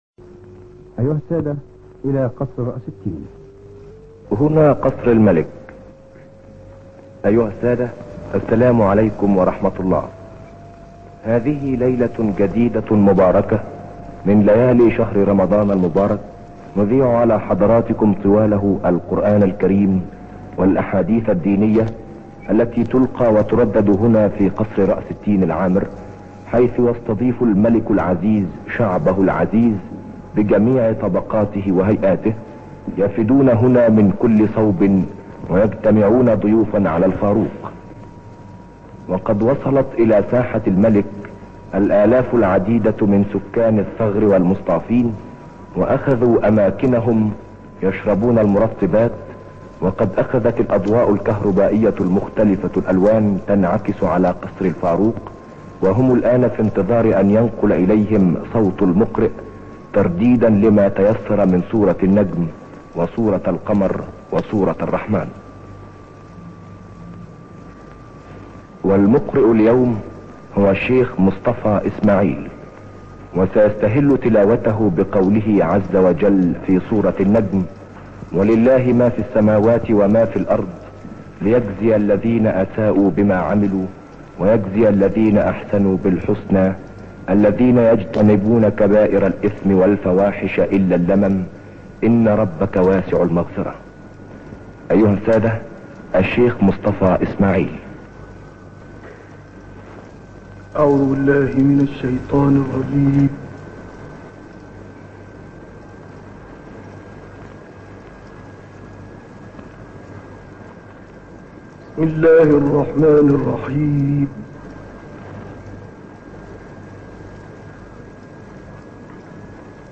تلاوت مصطفی اسماعیل در قصر ملک فاروق
گروه فعالیت‌های قرآنی: تلاوت شیخ مصطفی اسماعیل که در قصر ملک فاروق اجرا شده است، ارائه می‌شود.